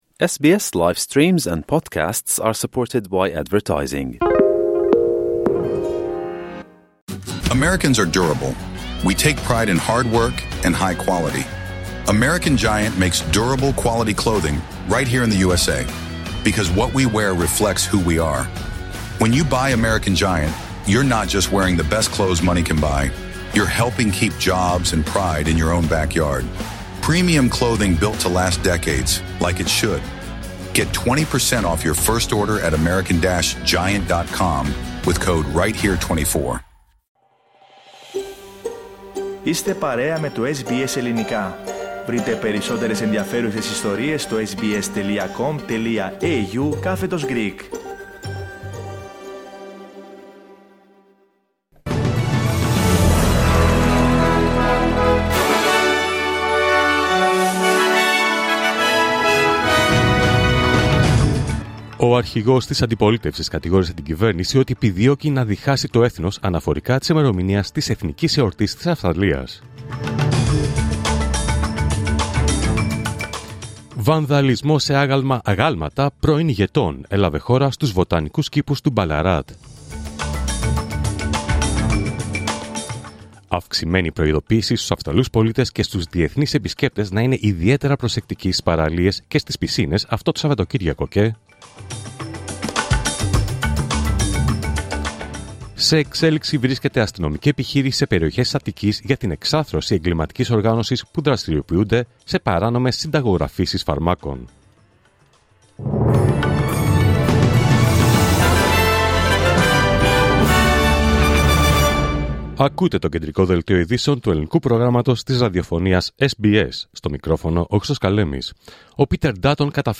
Δελτίο Ειδήσεων Παρασκευή 24 Ιανουαρίου 2025
News in Greek.